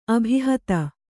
♪ abhihata